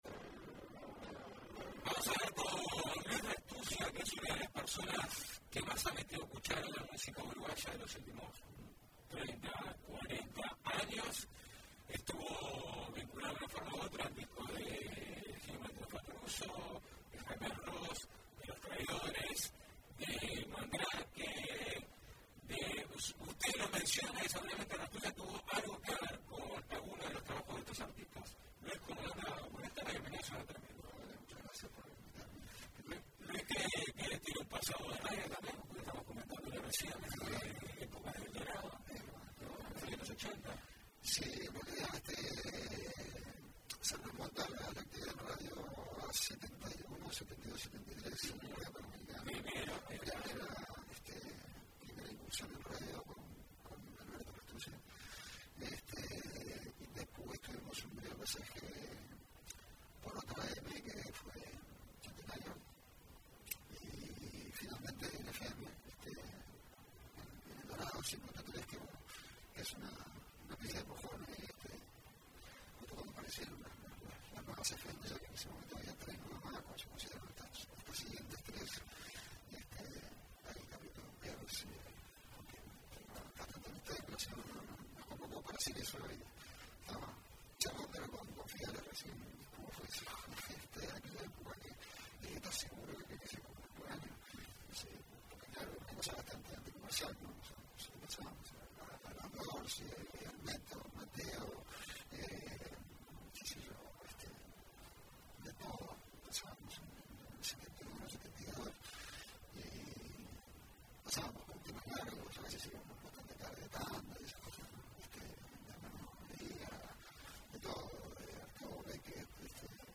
Le pedimos que viniera con algunas canciones de sus favoritas entre los discos en los que estuvo involucrado.